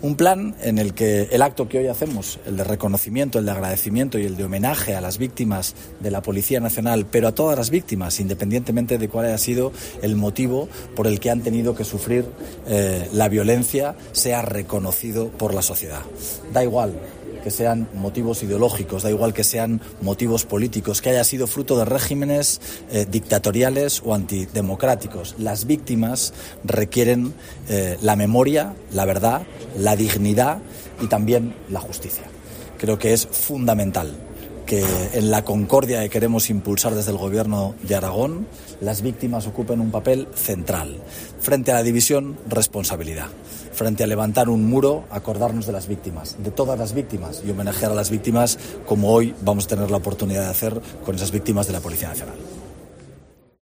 El presidente Azcón explica el objetivo del Plan de Concordia que aprobará el Consejo de Gobierno